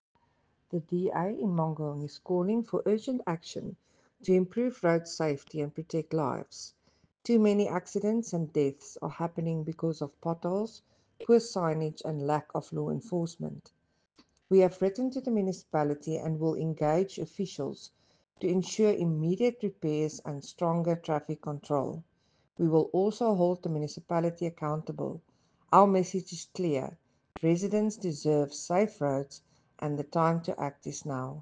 Afrikaans soundbites by Cllr Selmé Pretorius and